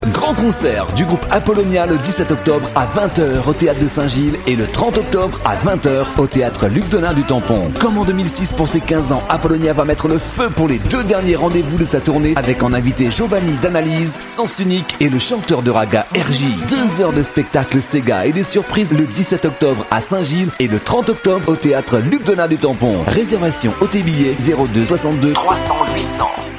Les Spots Pub Radio
le spot pour St Gilles et le Tampon